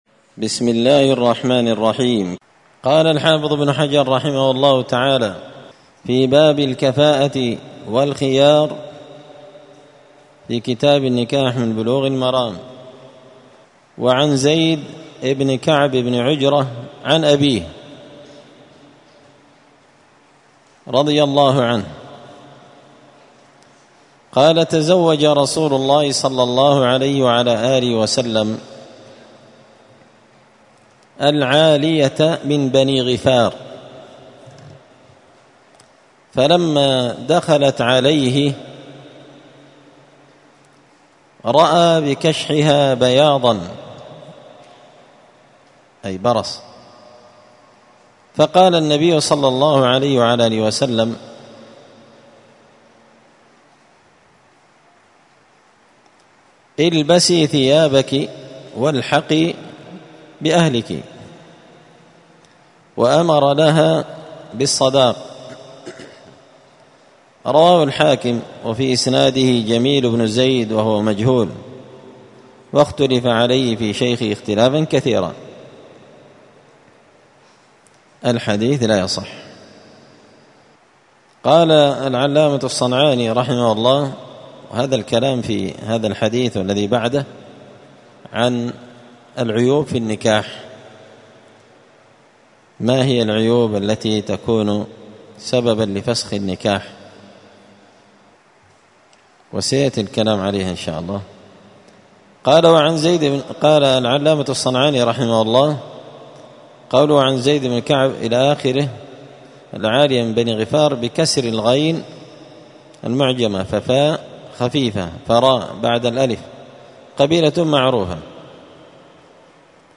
*الدرس 19 تابع أحكام النكاح {باب الكفاءة والخيار} عيوب في النكاح*
مسجد الفرقان_قشن_المهرة_اليمن